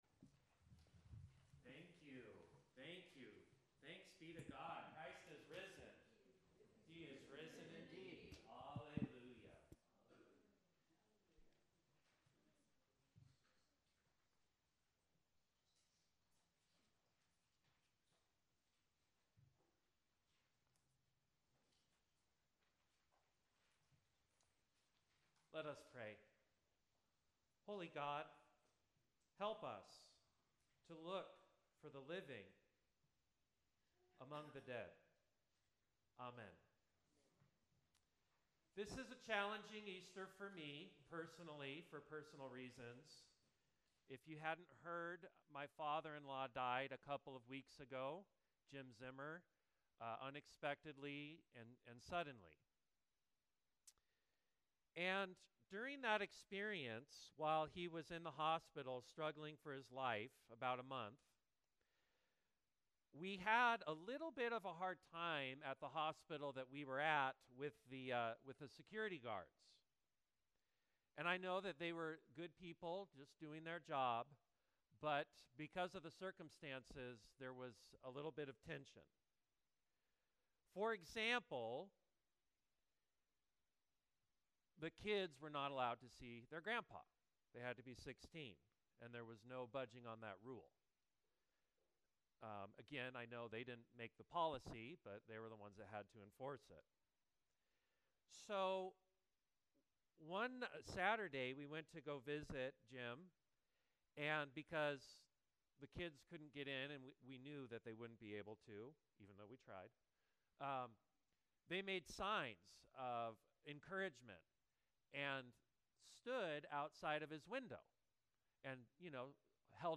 Sermon 04.20.25